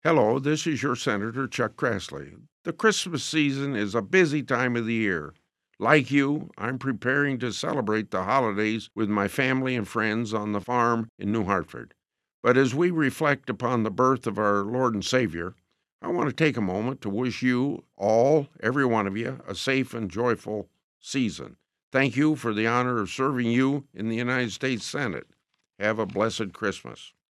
Sen. Grassley Christmas Message (27 seconds)